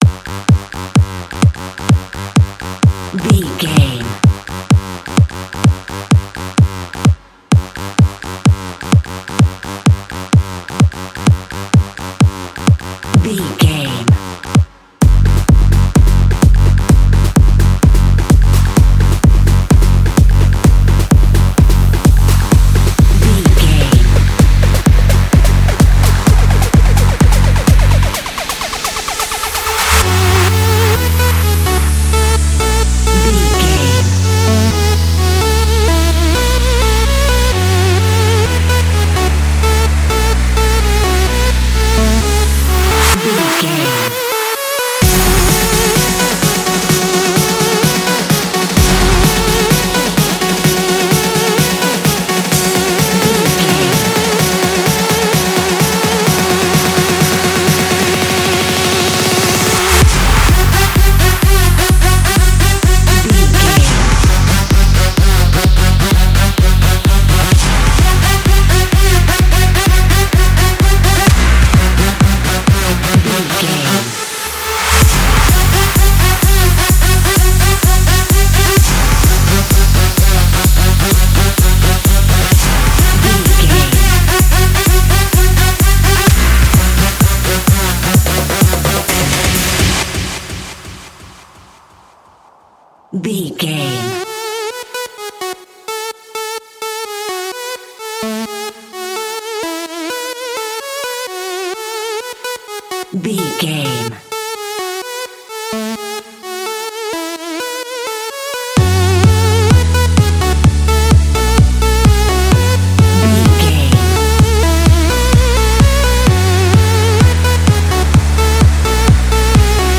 Epic / Action
Fast paced
Aeolian/Minor
F#
intense
driving
energetic
dark
synthesiser
drum machine
acid house
acid trance
uptempo
synth leads
synth bass